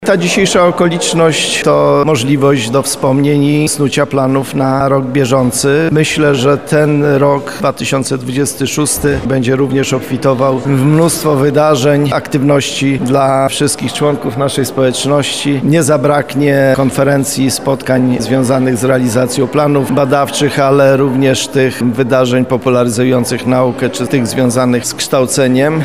Podczas spotkania przedstawione zostały ubiegłoroczne osiągnięcia uniwersytetu i jego studentów, a Rektor UMCS, Radosław Dobrowolski wspomniał również o kolejnych planach: